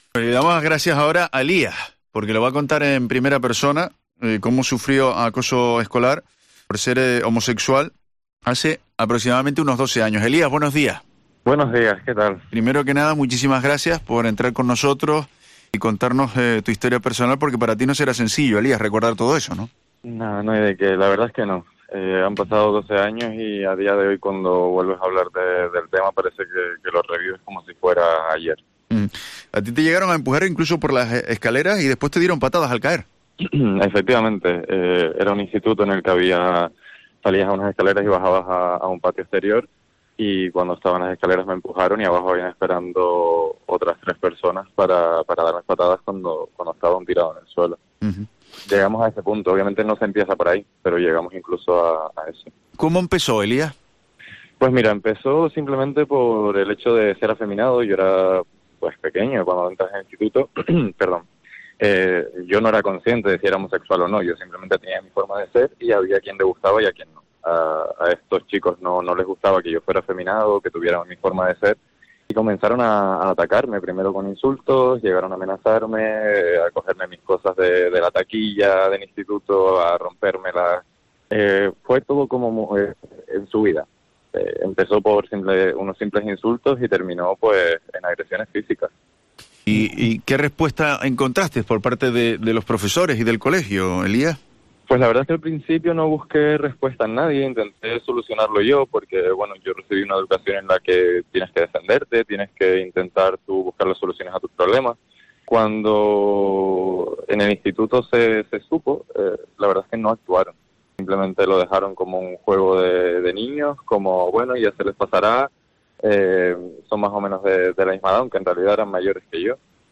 En los micrófonos de COPE Canarias ha relatado lo sucedido con el ánimo de poder ayudar a tantos niños y adolescentes que pasan por la misma situación.